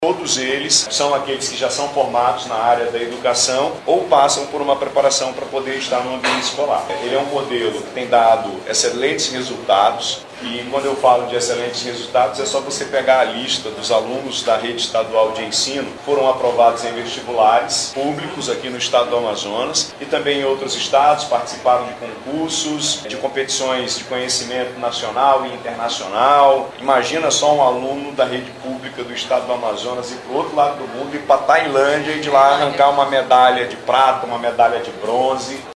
Durante a solenidade, o governador Wilson Lima destacou que a iniciativa busca oferecer um ambiente mais seguro e estruturado para o aprendizado dos estudantes.